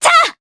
Artemia-Vox_Attack3_jp.wav